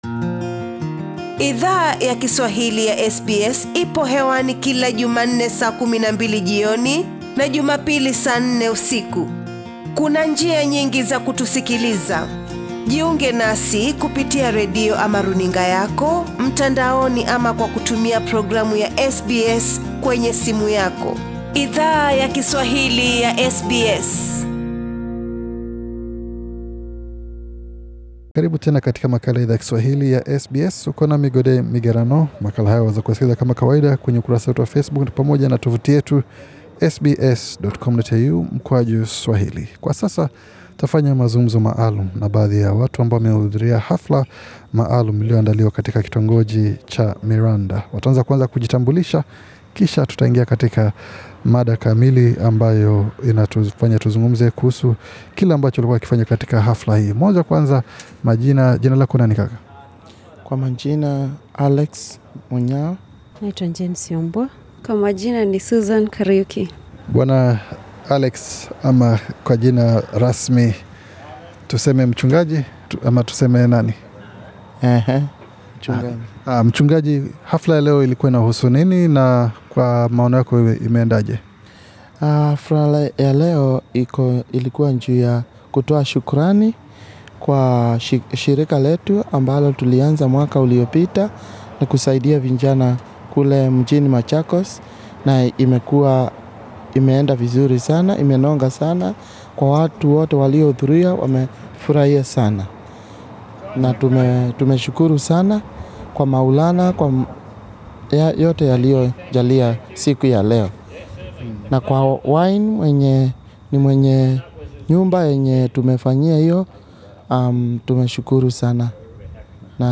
Kitongoji cha Mirandi mjini Sydney, NSW kili pata onjo ya vyakula vyaki Afrika, katika tukio maalum lamchango wa shirika la Shukrani Home Foundation ambalo hutoa misaada nchini Kenya. SBS Swahili ilizungumza na walio andaa tukio hilo.